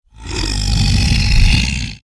戦闘 （163件）
クリーチャーボイス1.mp3